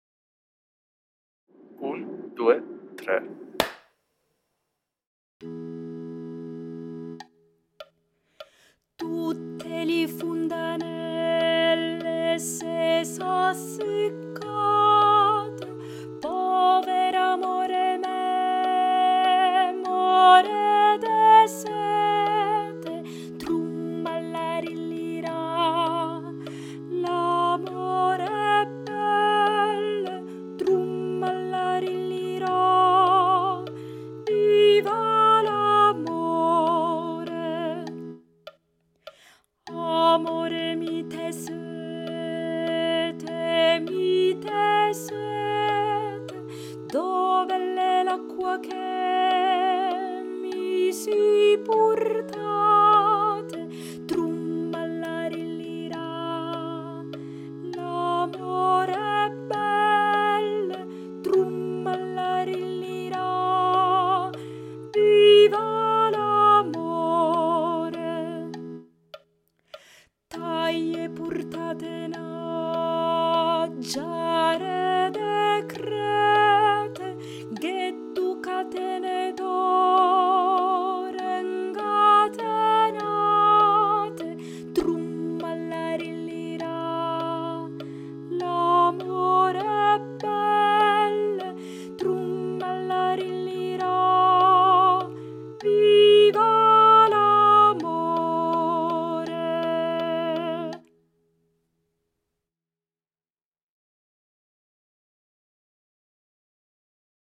🎧 Voce guida
Alti